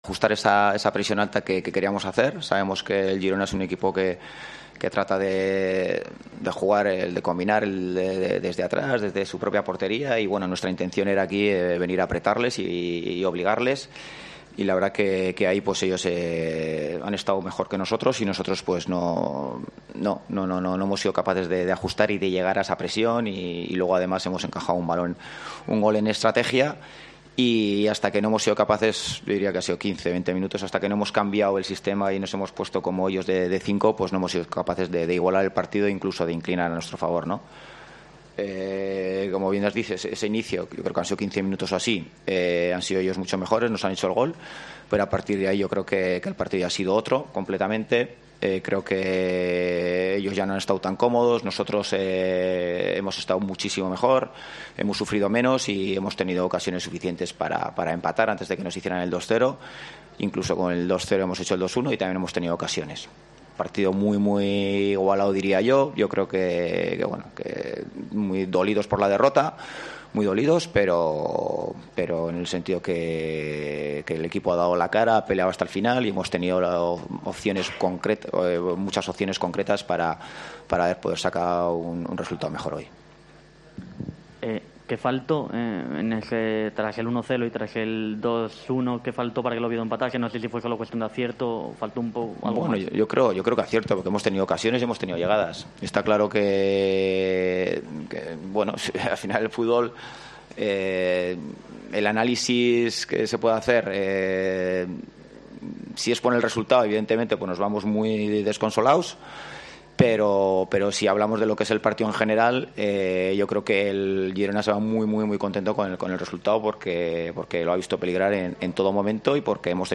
Rueda de prensa Ziganda (post Girona)